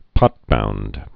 (pŏtbound)